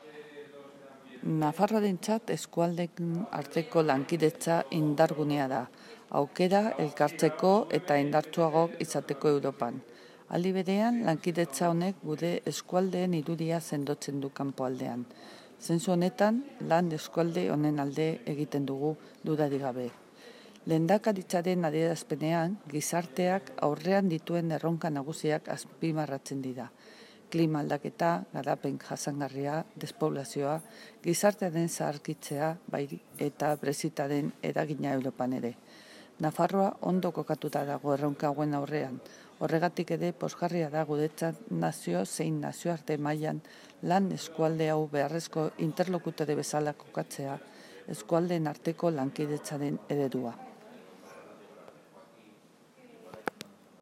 La consejera de Relaciones Ciudadanas Ana Ollo ha participado esta mañana en Jaca en el XXXVII Consejo Plenario de la Comunidad de Trabajo de los Pirineos